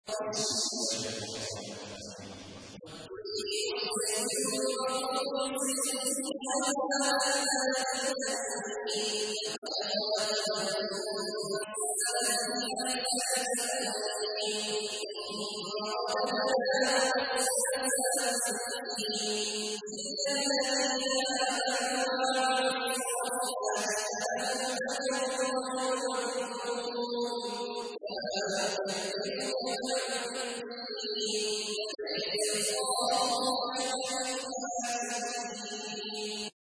سورة التين / القارئ عبد الله عواد الجهني / القرآن الكريم / موقع يا حسين